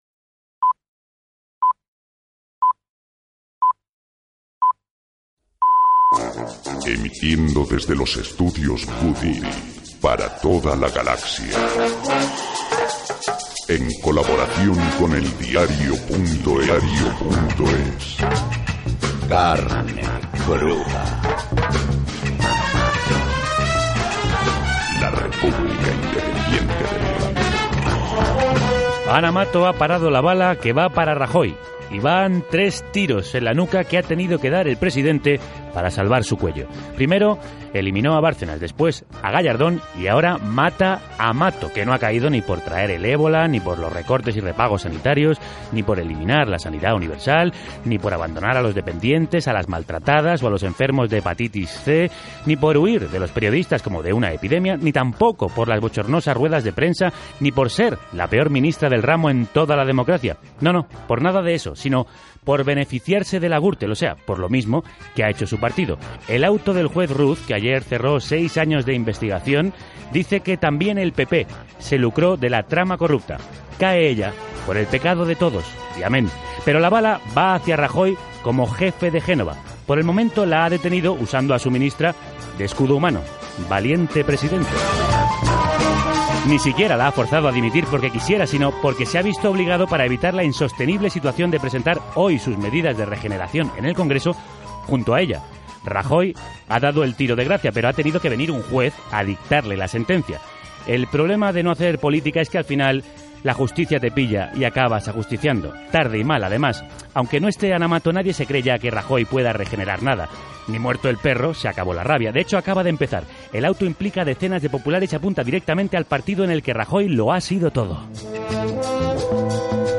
Pero en nuestro país los malos tratos policiales se silencian, ocultan y minimizan en las instituciones y tribunales. Por esa razón, rompemos el silencio con un programa sobre la tortura en nuestro país con especialistas, médicos y estremecedores testimonios de denunciantes.